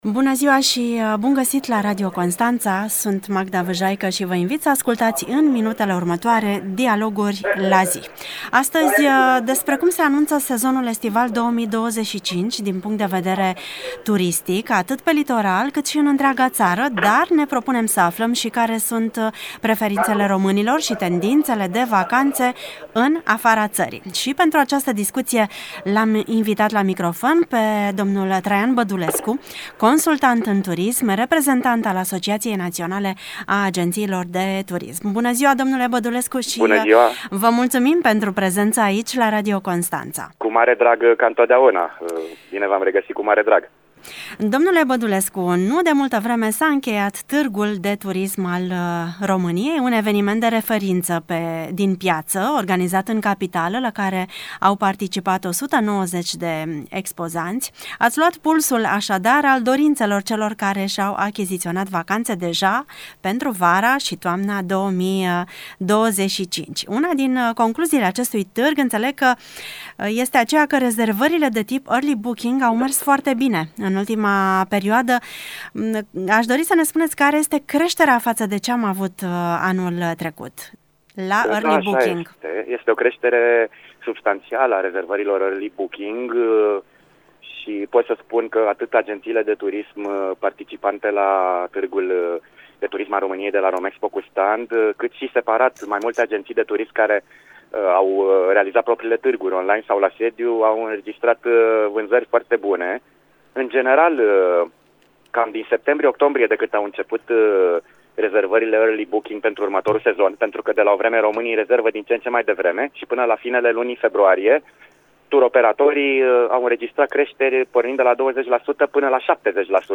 Invitatul de astăzi